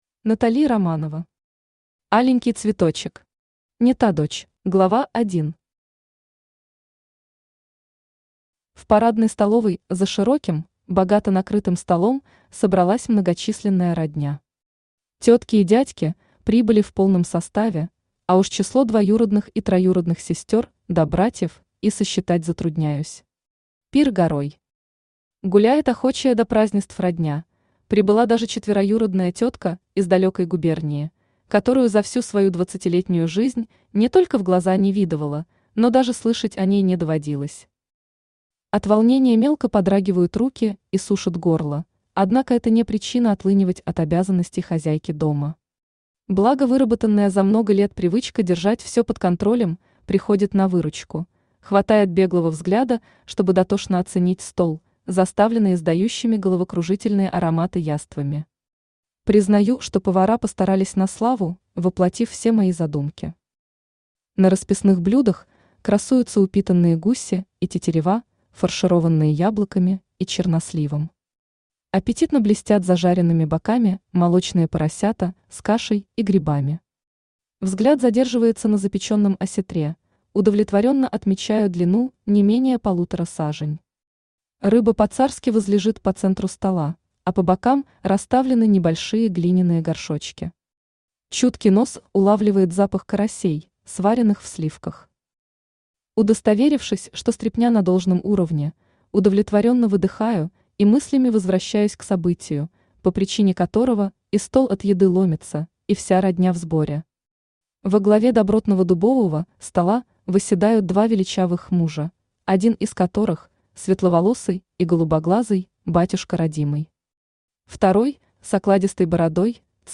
Аудиокнига Аленький цветочек.